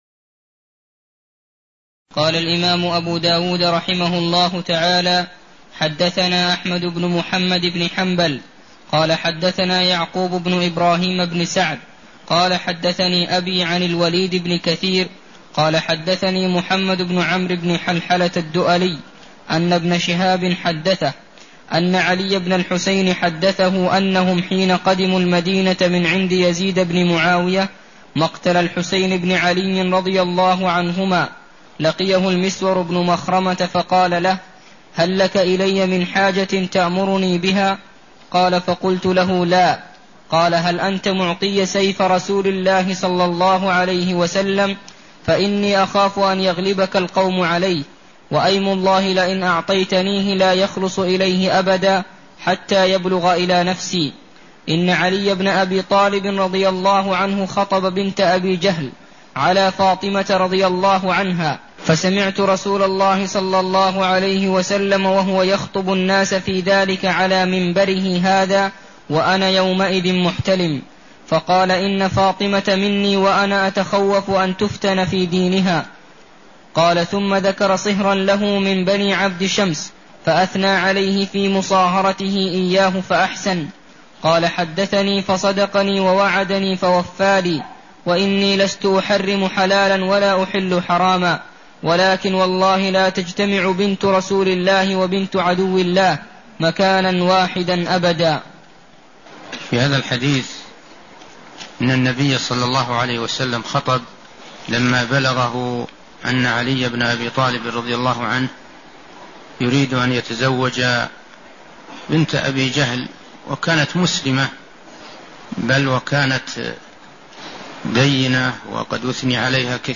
المكان: المسجد النبوي الشيخ: عبدالله الغنيمان عبدالله الغنيمان باب مايكره أن يجمع بينهن من النساء إلى باب الولي (03)